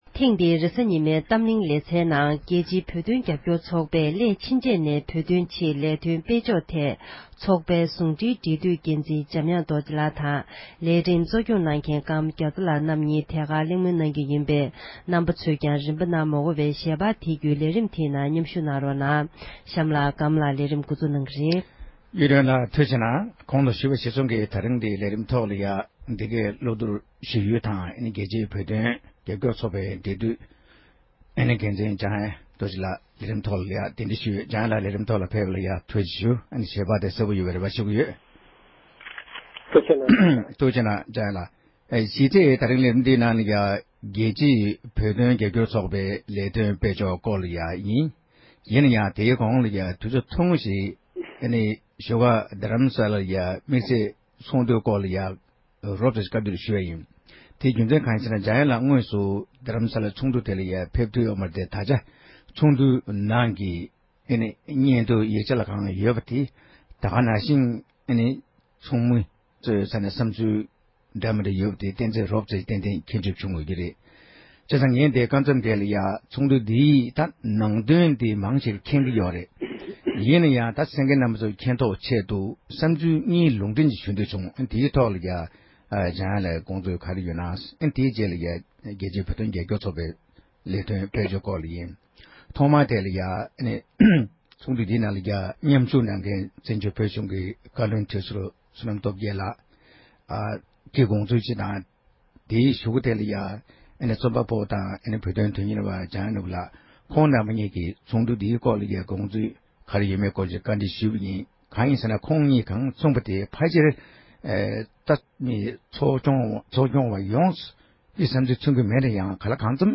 བགྲོ་གླེང་གནང་བ་ཞིག་གསན་རོགས་གནང༌༎